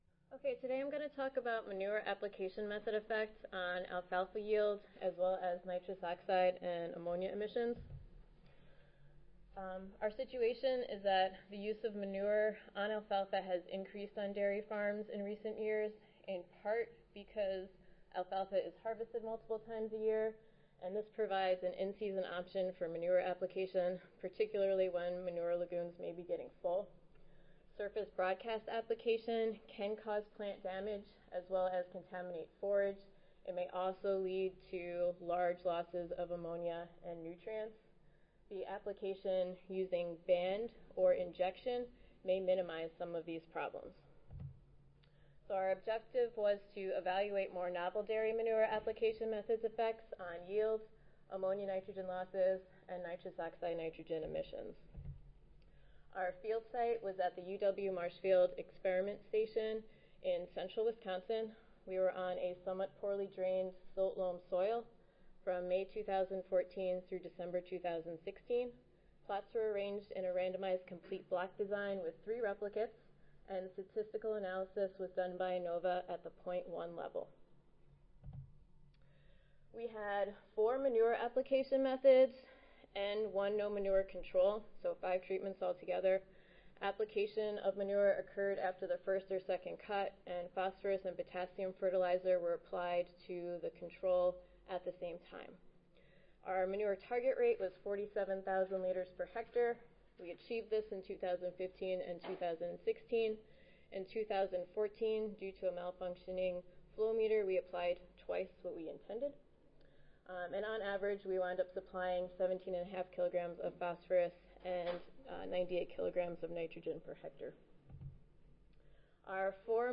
See more from this Division: ASA Section: Environmental Quality See more from this Session: Greenhouse Gas Emissions from Integrated-Crop Livestock System Oral